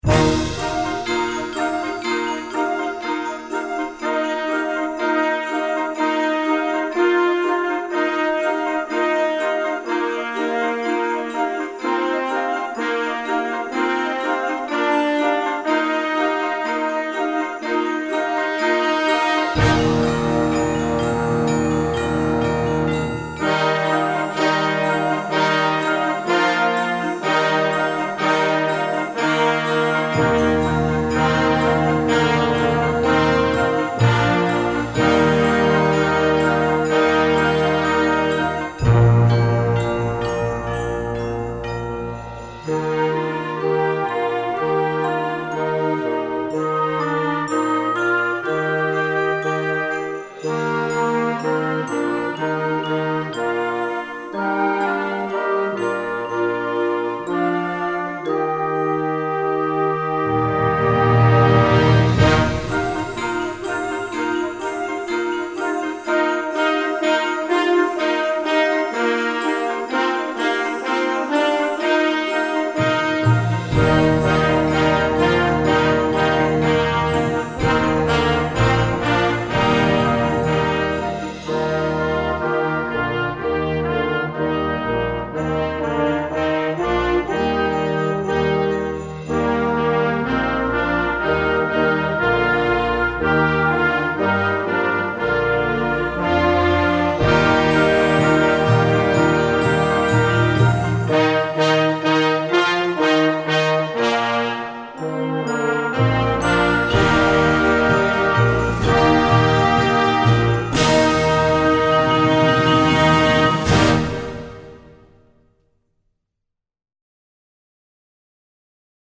Gattung: A Holiday Fanfare for Band
Besetzung: Blasorchester